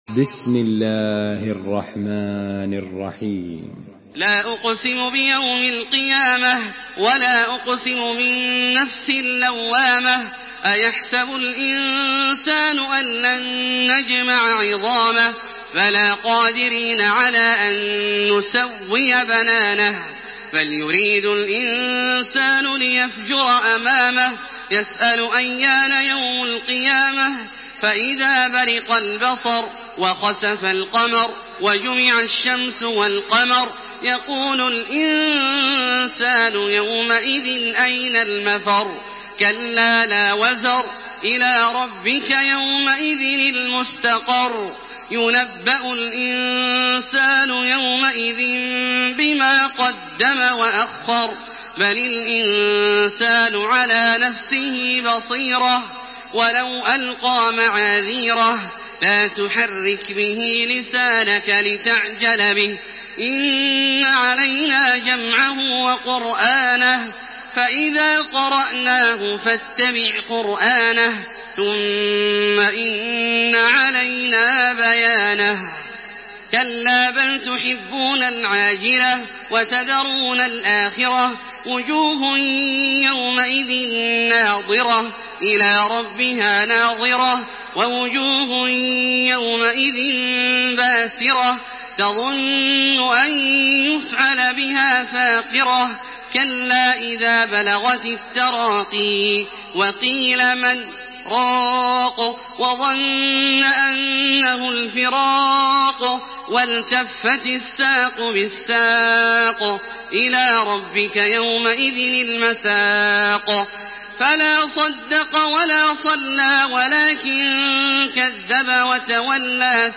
تحميل سورة القيامة بصوت تراويح الحرم المكي 1428
مرتل حفص عن عاصم